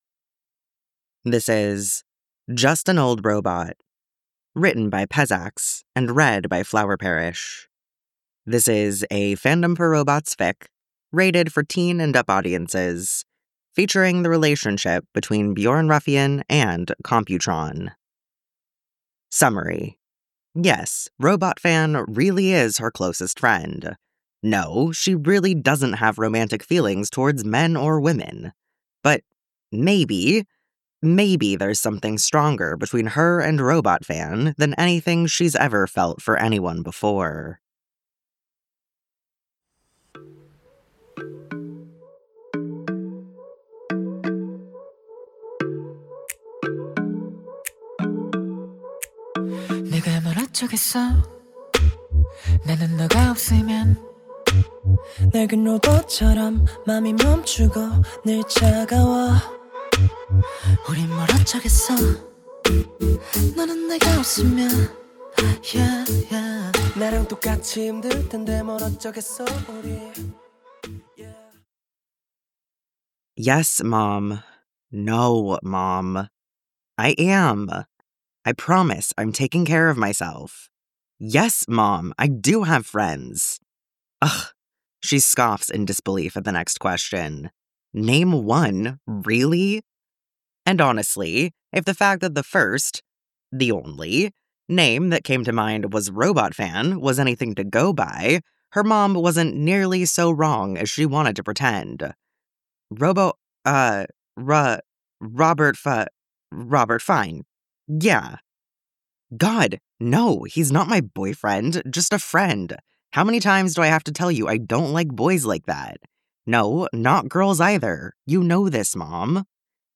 Robot SFX Version download mp3: here (r-click or press, and 'save link') [13 MB, 00:13:33]